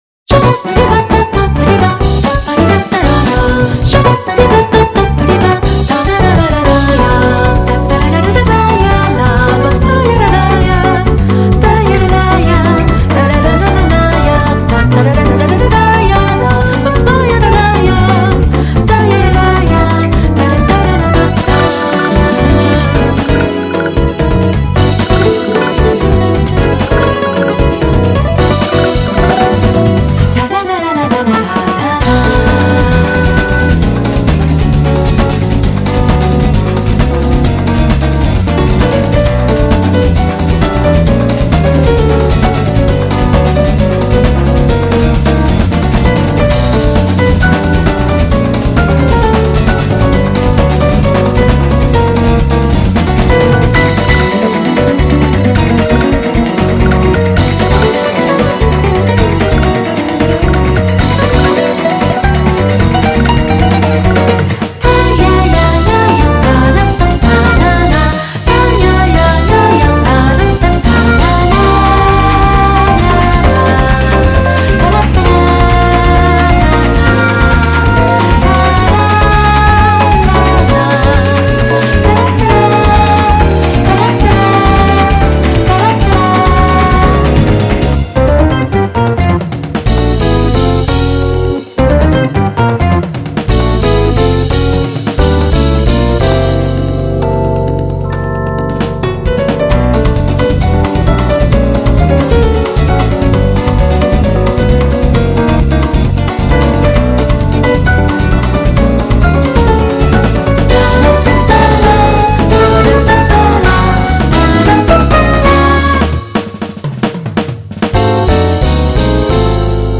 드럼이 정교하게 어려워서 그런것이지 브라질리안 느낌의 피아노곡입니다.